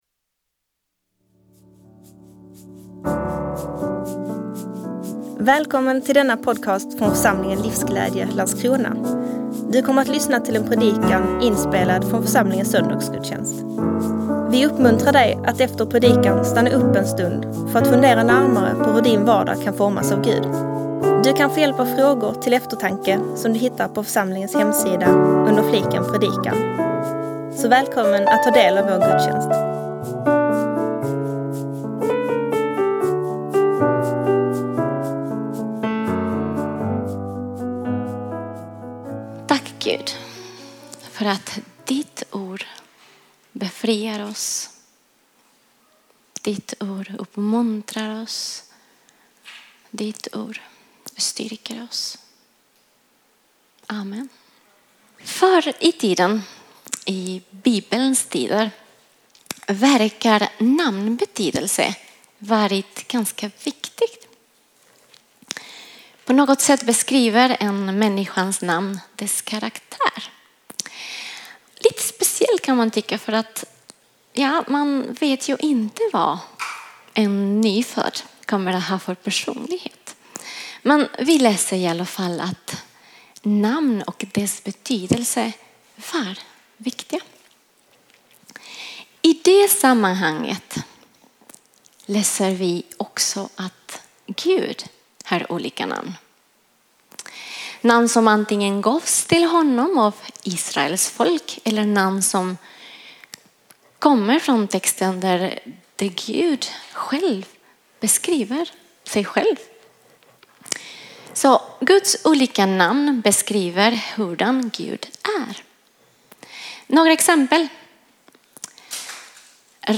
predikar.